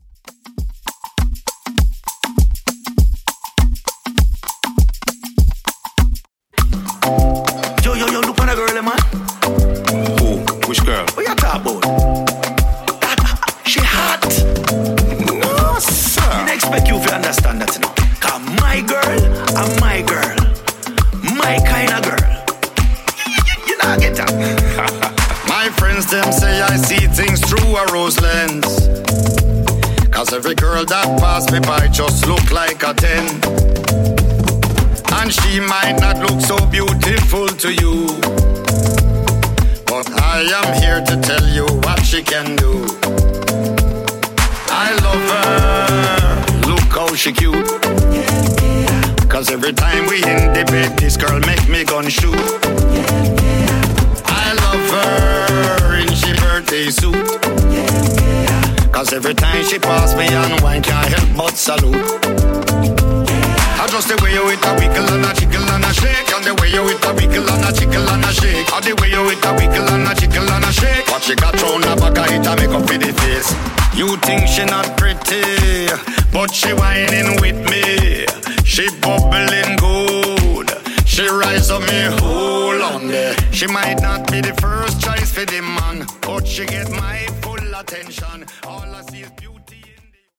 Reggae Afrobeat)Date Added